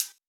Closed Hats
Southside Closed Hatz (10).wav